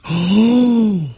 Man Gasp 13 Sound Effect Free Download
Man Gasp 13